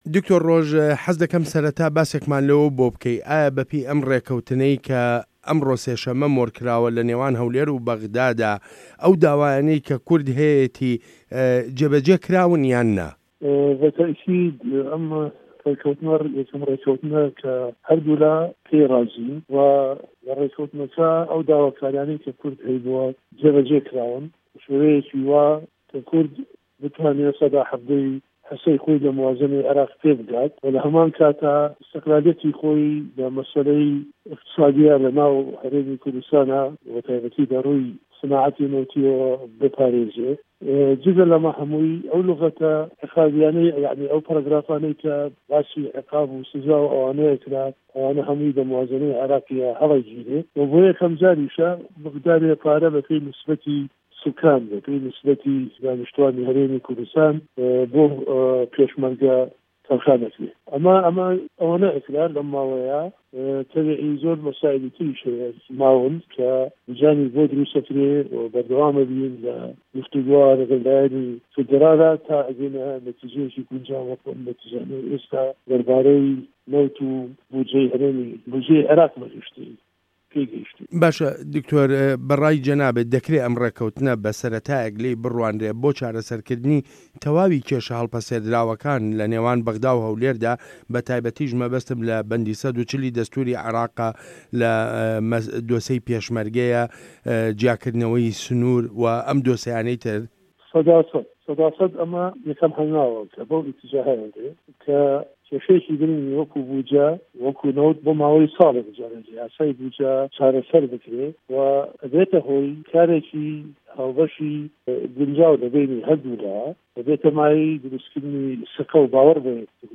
وتووێژ له‌گه‌ڵ دکتۆر ڕۆژ شاوێس